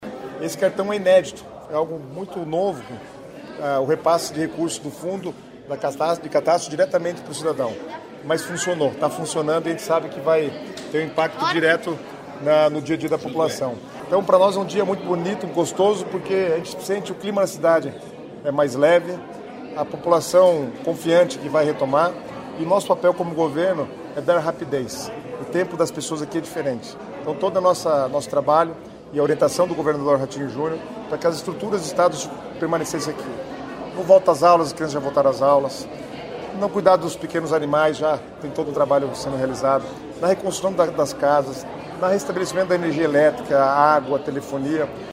O secretário das Cidades, Guto Silva, disse que o estado está focando para oferecer o apoio em Rio Bonito do Iguaçu com agilidade.